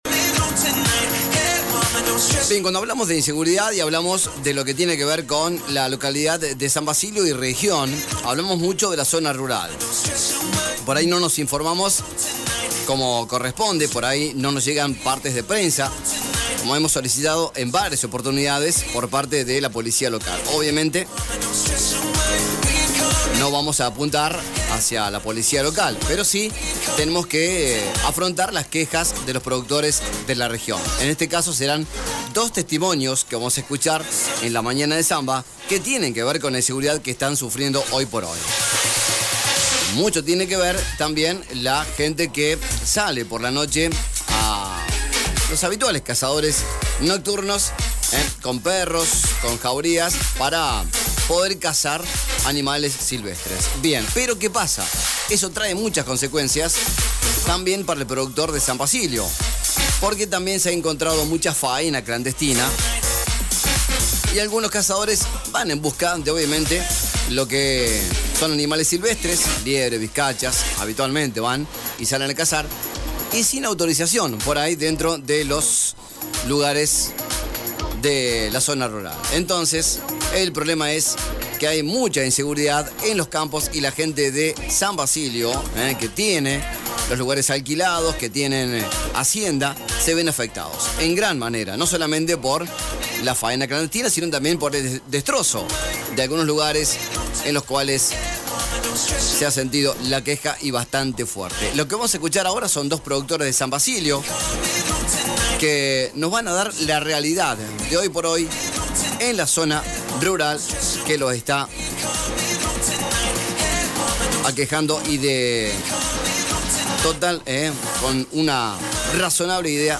A continuación escuchamos audios de vecinos de la zona rural damnificados: